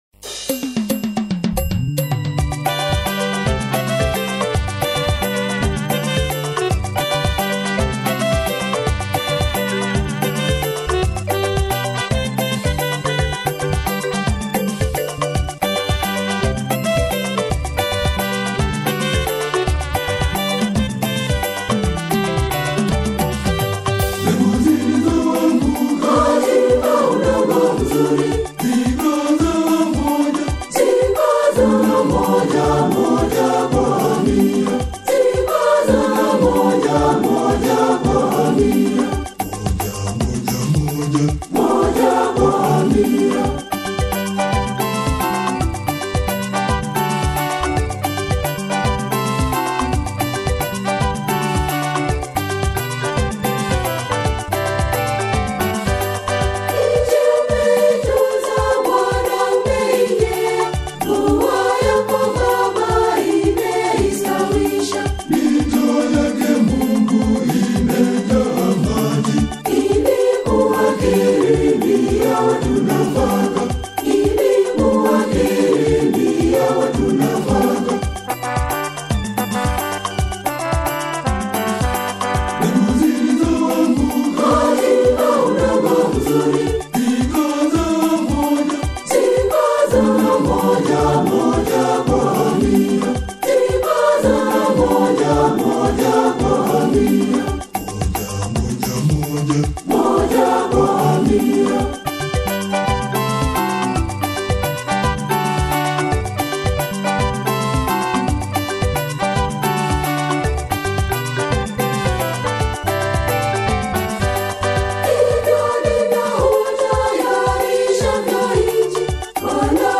a reflective and spiritually profound single